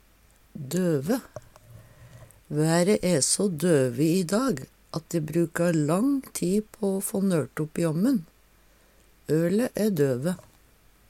døve - Numedalsmål (en-US)